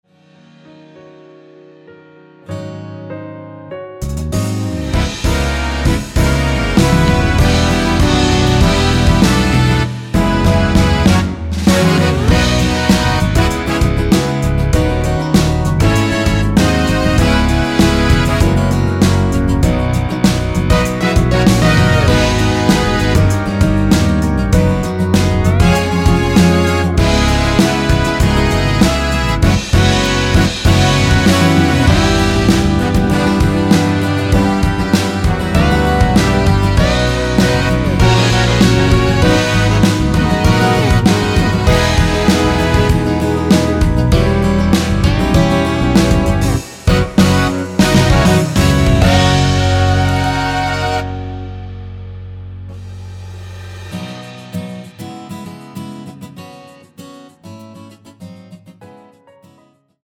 미리듣기는 “후 살며시 네가 불어와” 부터 시작됩니다.
원키에서(-1)내린(1절+후렴)으로 진행되는 MR입니다.
앞부분30초, 뒷부분30초씩 편집해서 올려 드리고 있습니다.